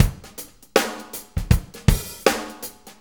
Shuffle Loop 23-09.wav